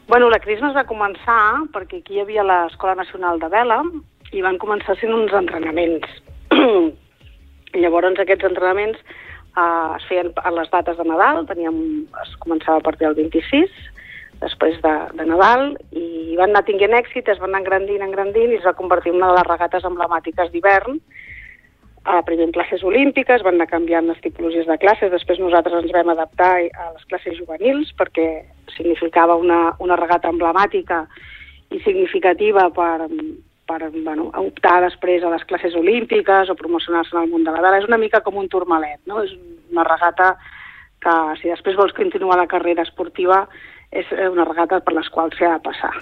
Supermatí - entrevistes